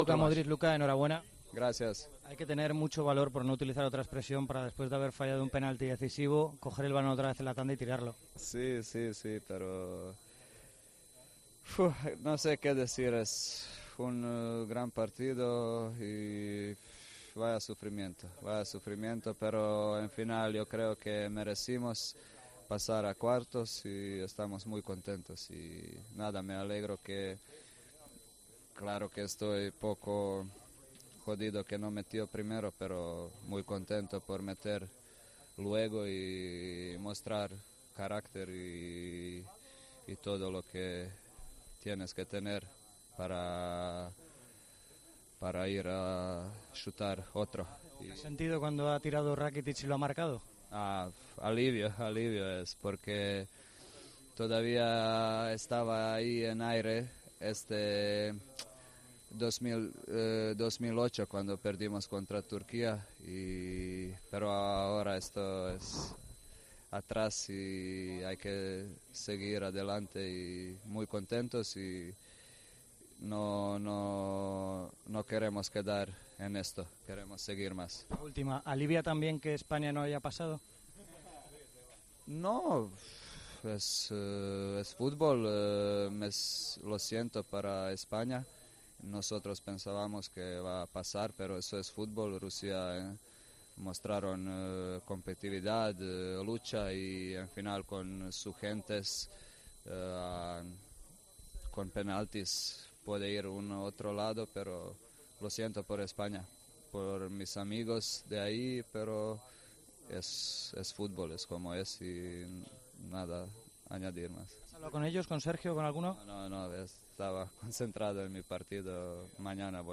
AUDIO: Hablamos con el capitán de Croacia que falló un penalti en la prórroga ante Dinamarca, pero que luego transformó otro en la tanda de penaltis...